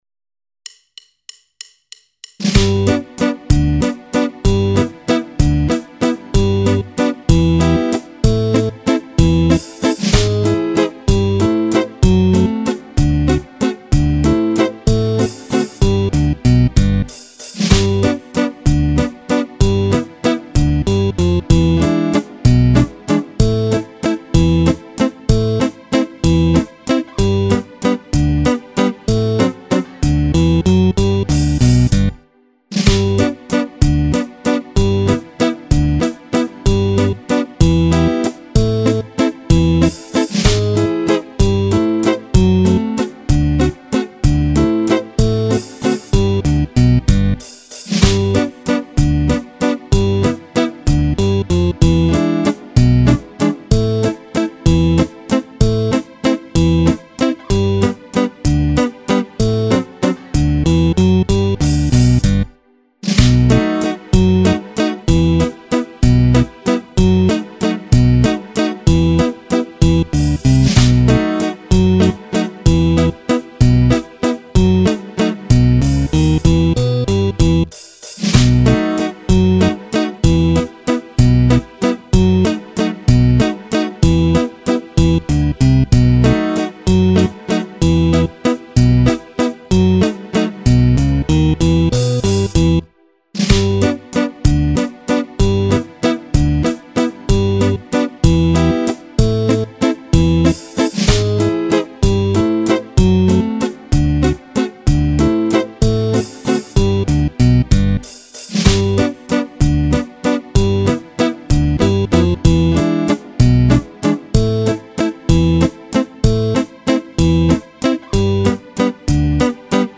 Due ballabili per Fisarmonica
Tango